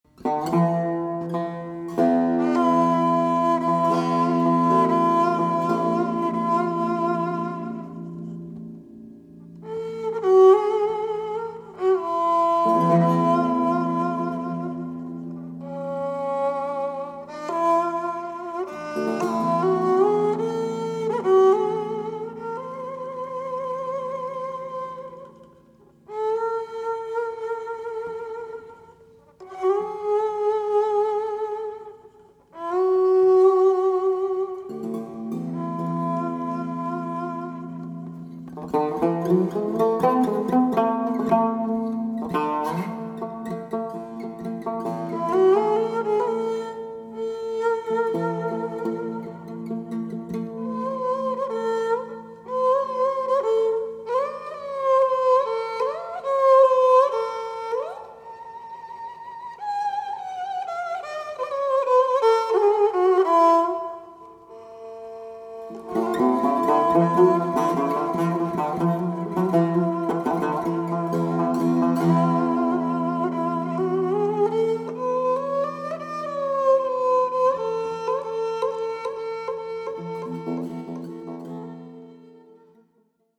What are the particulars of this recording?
Recorded on October 5 & 6,1999 in Walnut Creek, California